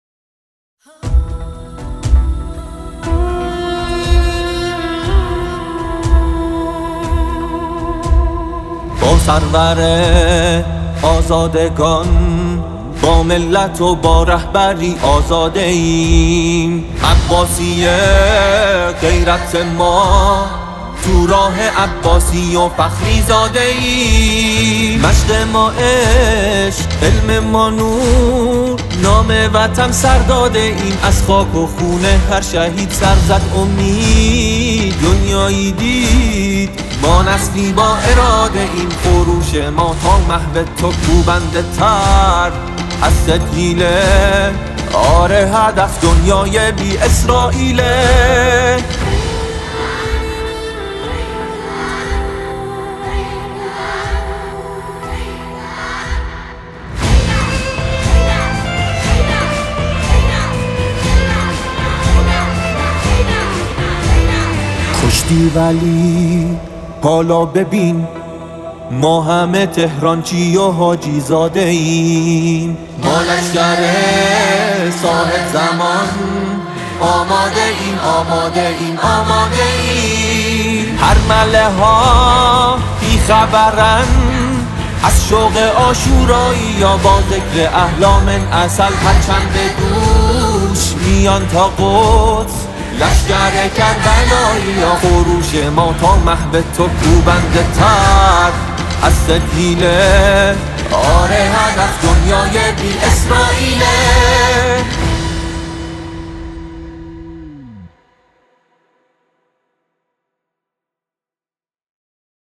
نماهنگ حماسی